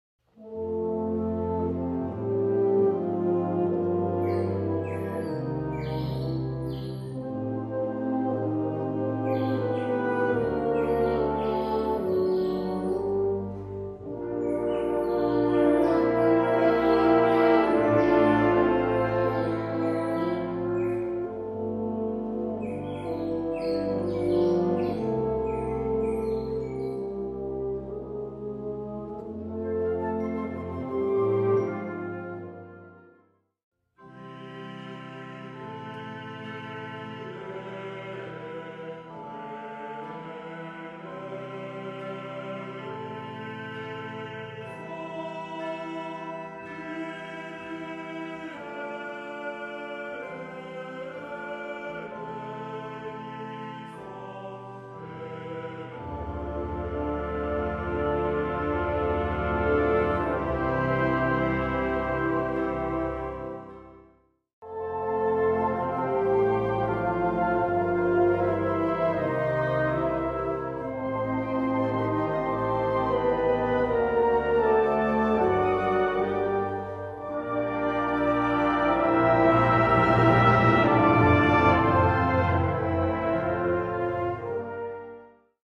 F Major（原調）
とても美しく優雅な小品です。